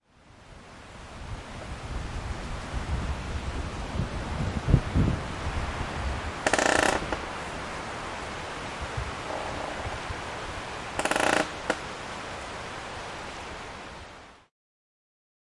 长椅吱吱声3
描述：老木凳吱吱作响的声音
Tag: 嘎嘎作响